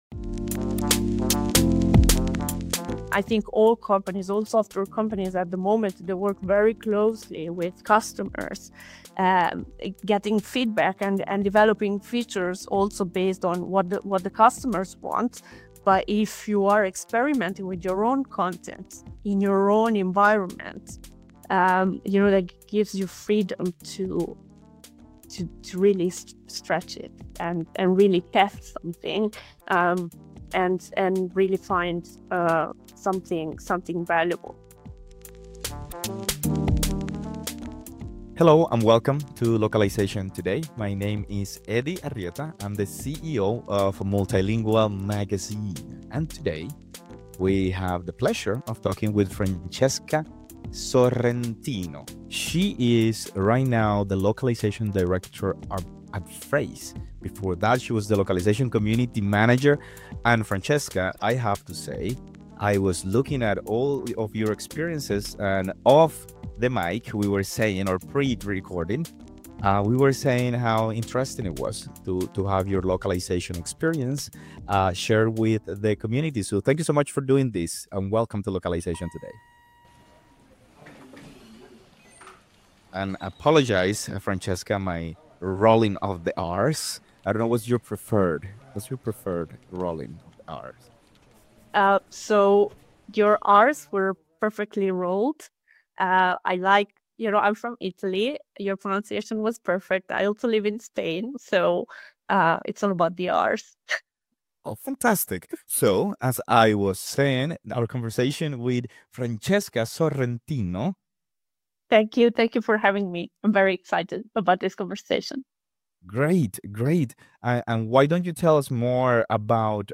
This conversation highlights the bold strategies that set Phrase apart and inspire the localization industry.